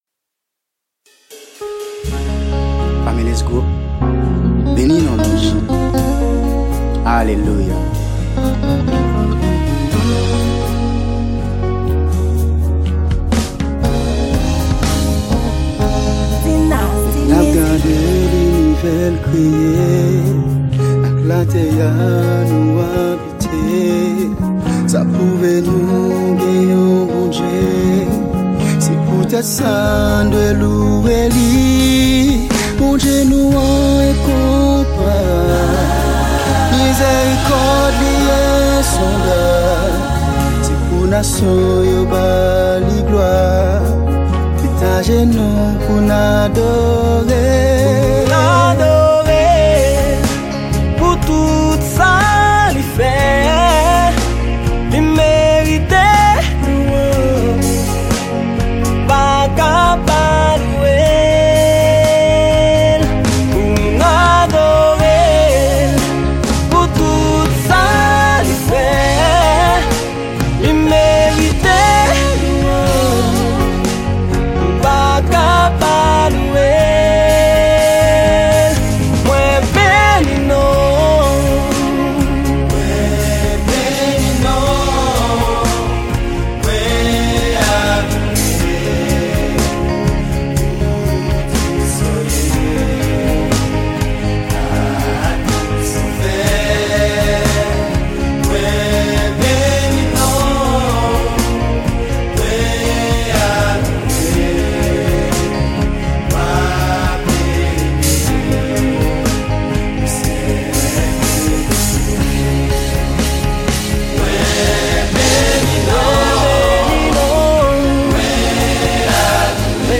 Genre: Gospel.